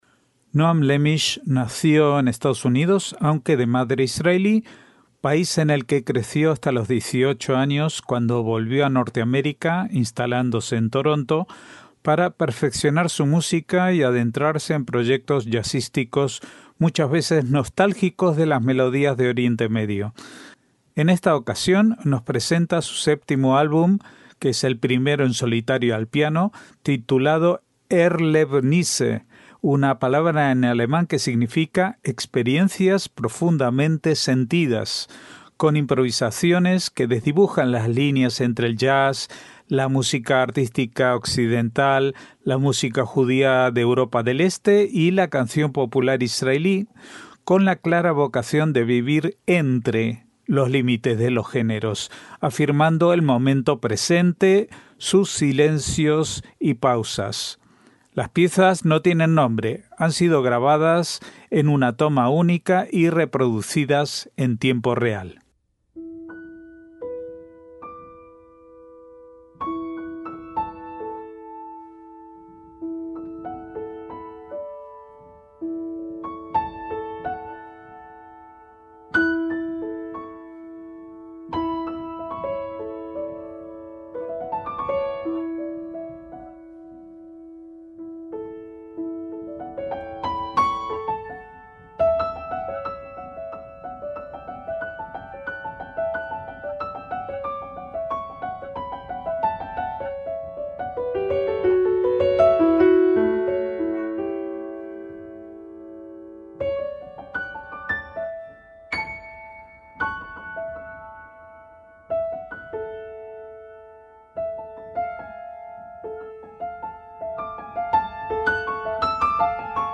MÚSICA ISRAELÍ
solitario al piano